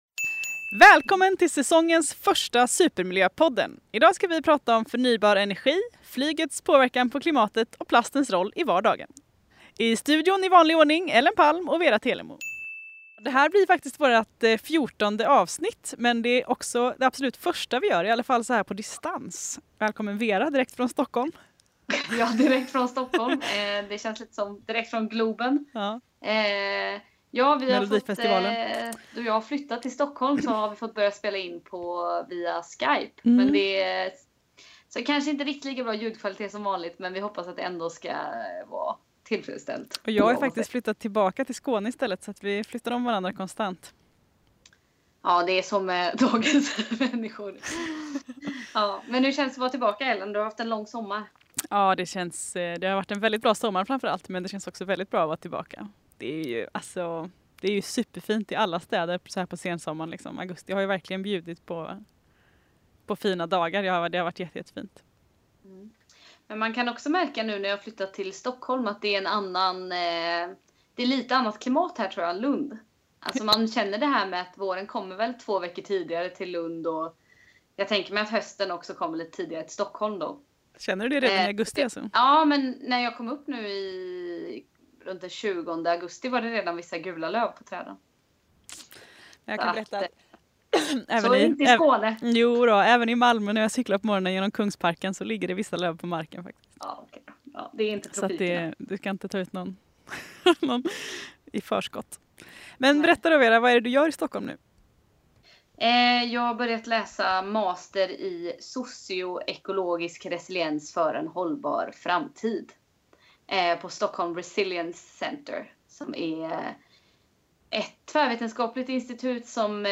I studion: